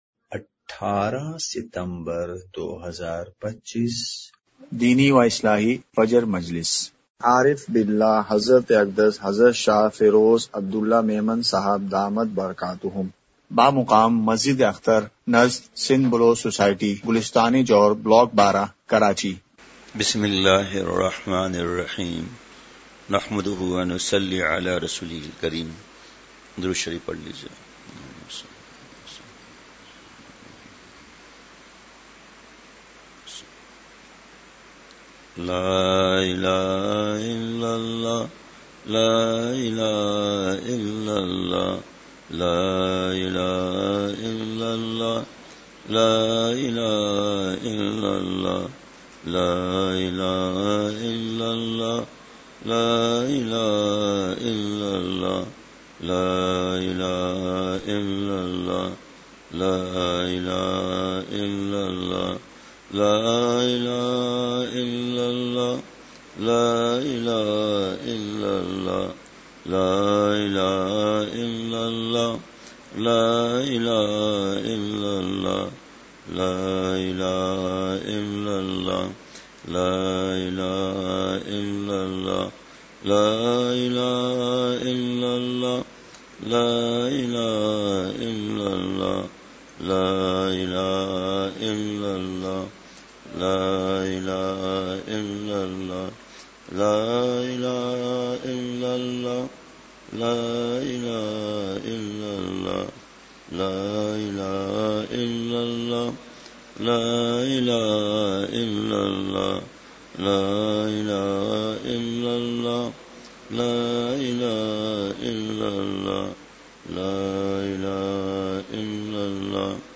*مقام:مسجد اختر نزد سندھ بلوچ سوسائٹی گلستانِ جوہر کراچی*